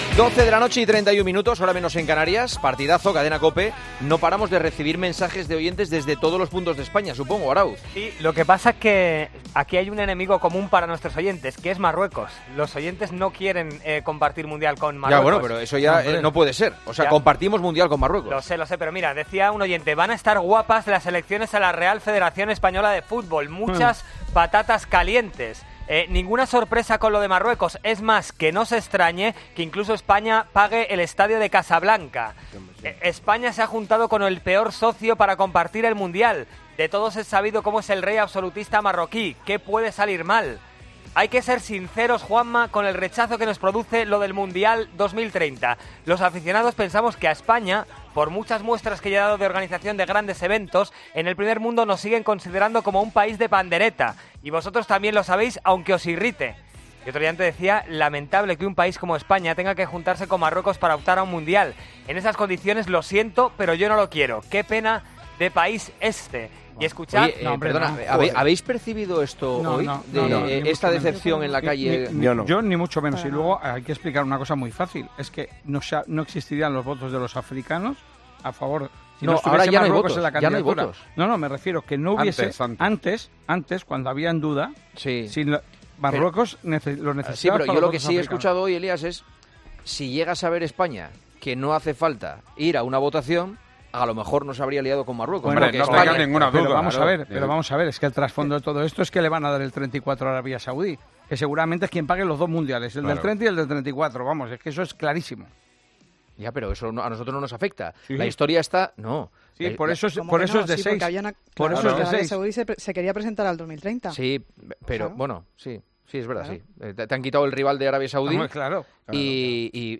El Partidazo de COPE Oyentes de El Partidazo de COPE muestran su rechazo a que España organice el Mundial con Marruecos Numerosos oyentes mostraron su sentir a través del whatsapp de El Partidazo de COPE. Los comentaristas, junto a Juanma Castaño, analizaron de dónde viene esa animadversión.